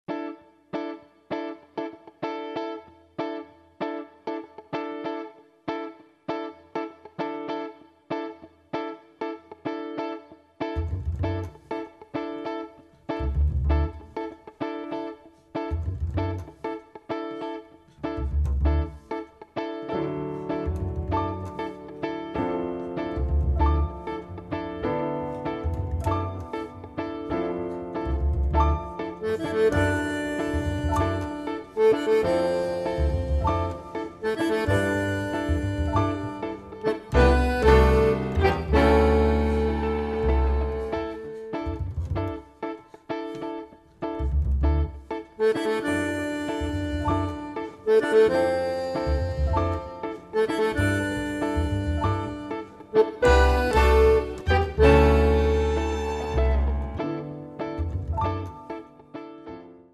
bandoneon
pianoforte
violino
chitarra
basso
Mai freddo, mai alchemico seppur tecnicamente perfetto.